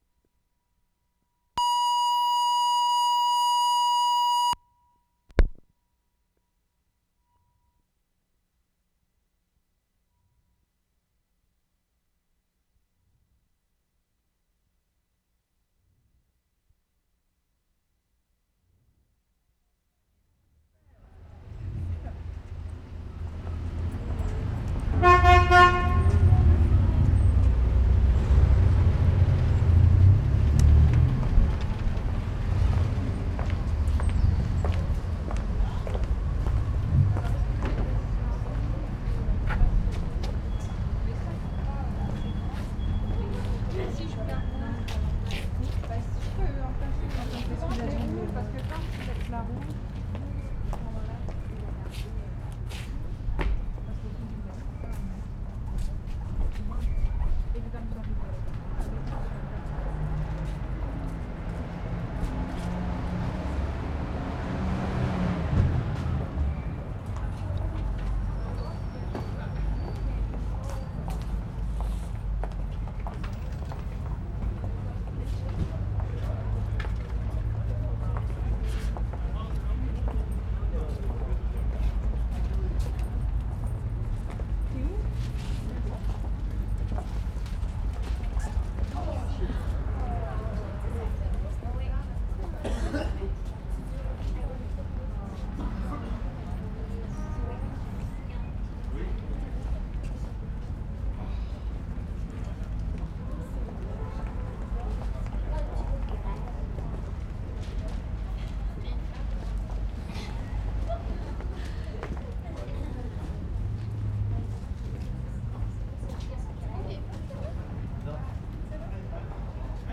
QUEBEC CITY, QUEBEC Oct. 28, 1973
AMBIENCE IN ARTIST'S ALLEY 5'26"
1. Relatively quiet. Lots of people's voices and footsteps. Bus stopping and idling (2'30"), motorbike immediately after, car horn (3'45"). Very busy ambience.